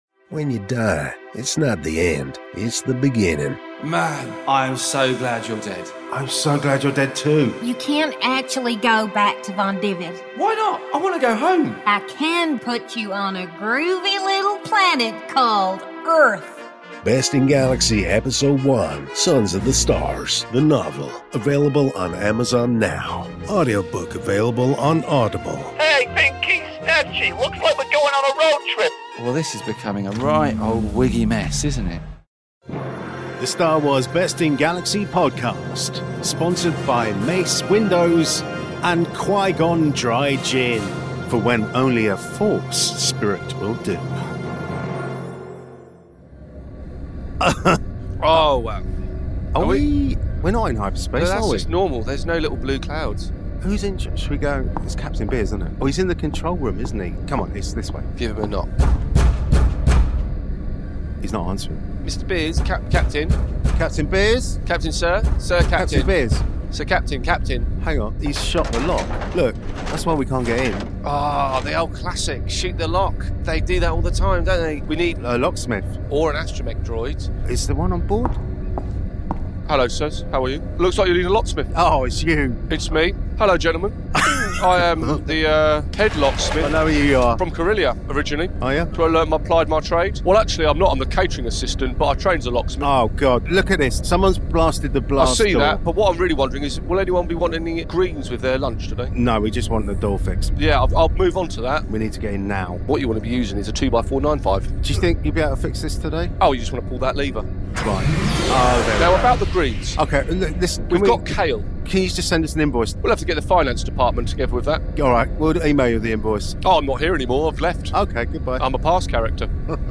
S02 E04 of the only improvised unofficial Star Wars based parody sitcom podcast in the galaxy!!